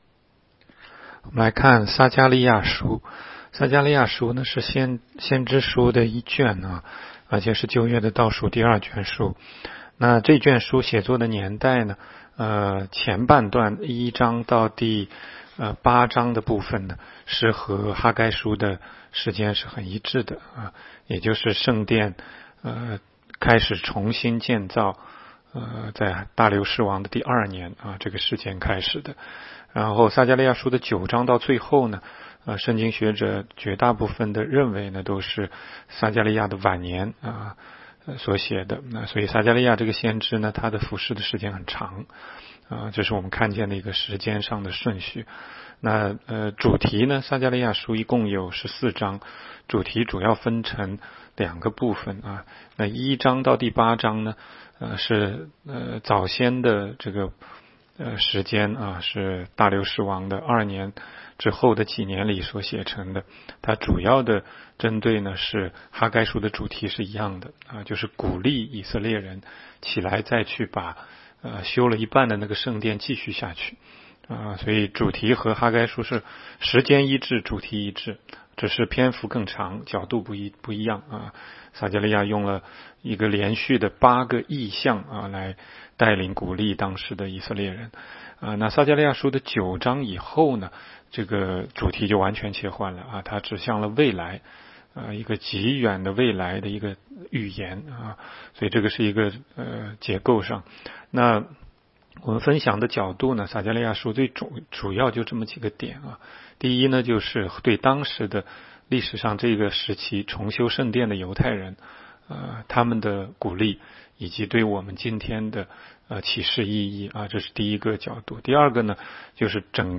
每日读经
每日读经-亚1章.mp3